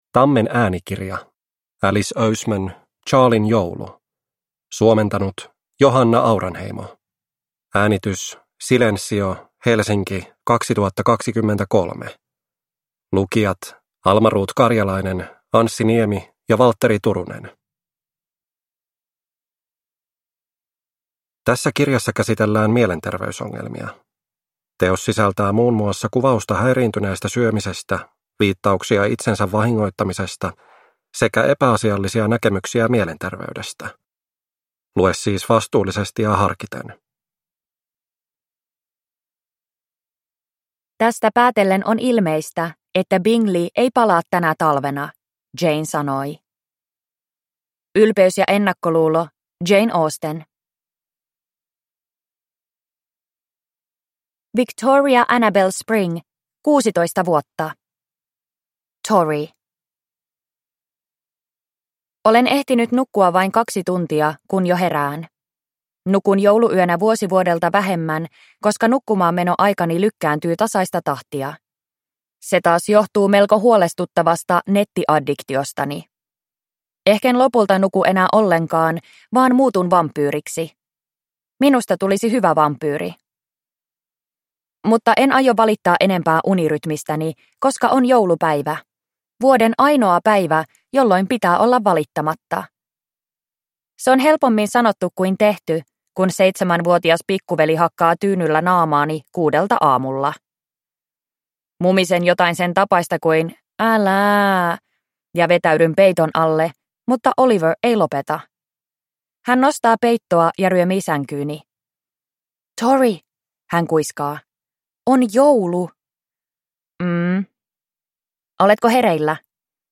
Charlien joulu – Ljudbok